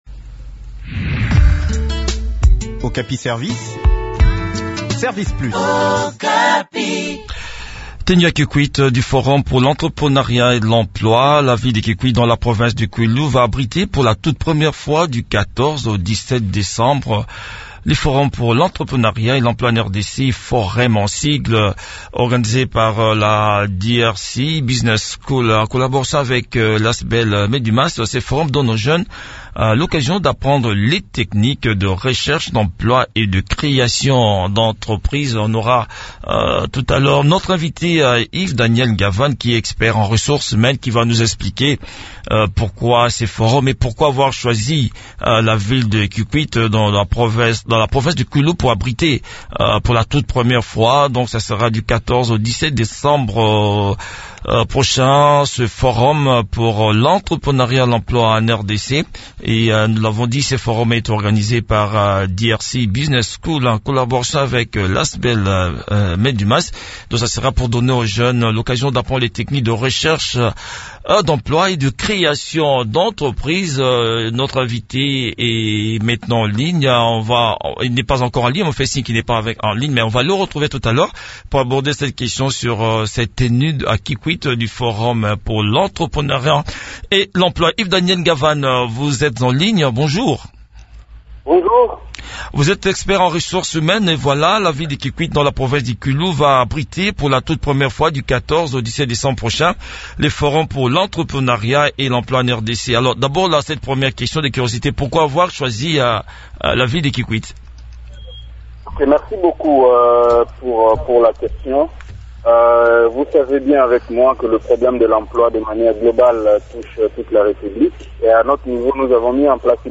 s’entretient